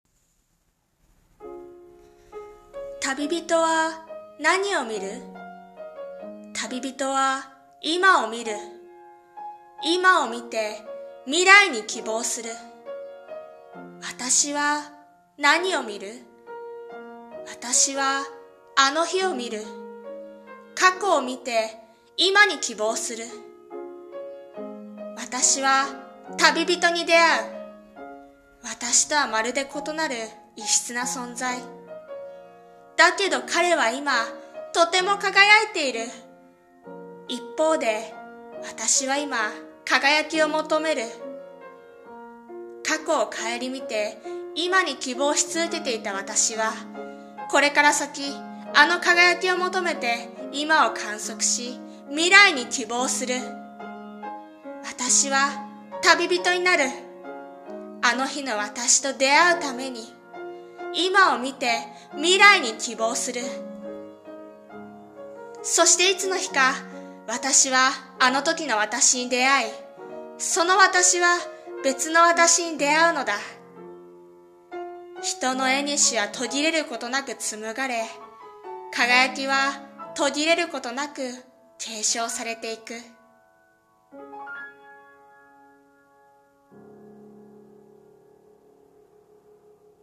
さんの投稿した曲一覧 を表示 朗読台本/一人声劇『めぐる縁』